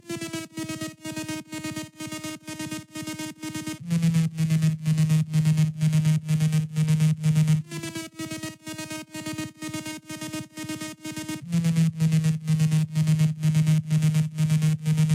STCR2_BFA_126_Kit_Loop_Disco_Drop_Synth_Lead_D#min
amsterdam-bass.mp3